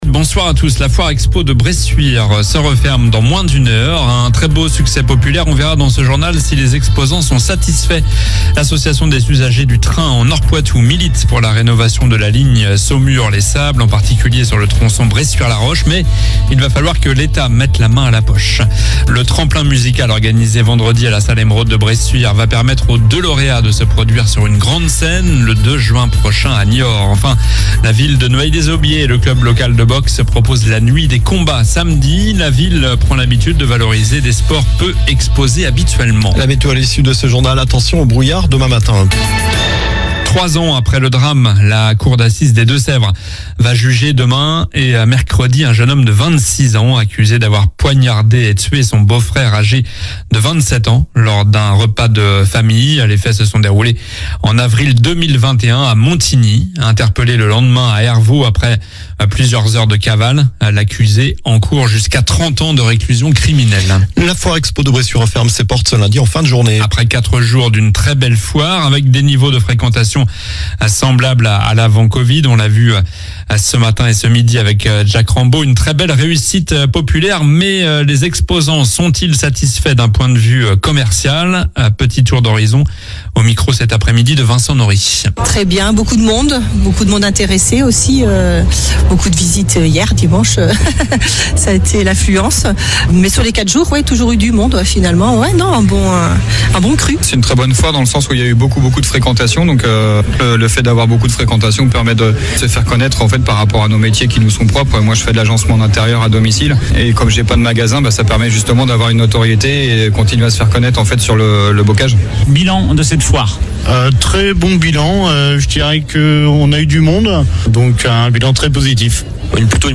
Journal du lundi 18 mars (soir)